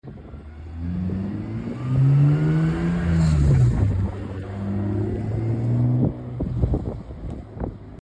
Amazing sounding Mark Mp3 Sound Effect (VOLUME 🆙!!!) Amazing sounding Mark IV Supra! Leaving Chester cars and coffee!